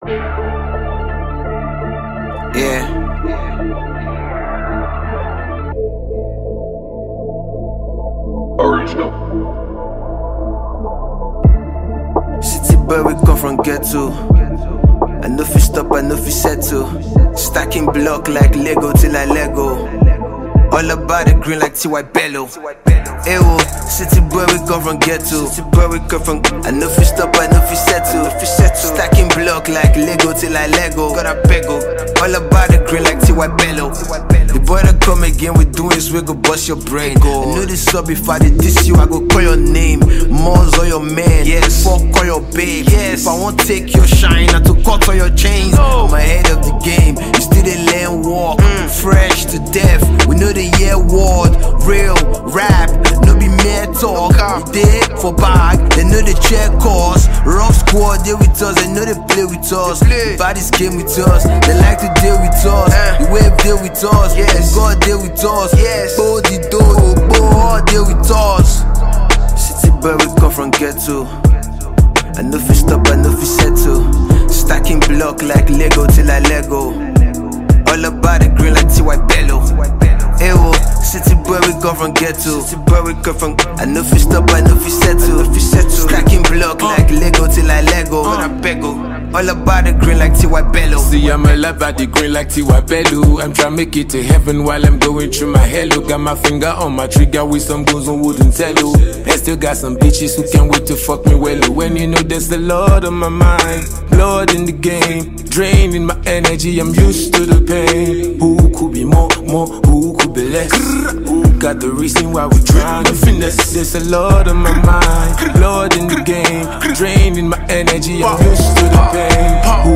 rappers
hiphop